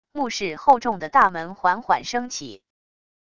墓室厚重的大门缓缓升起wav音频